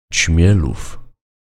Ćmielów [ˈt͡ɕmʲɛluf]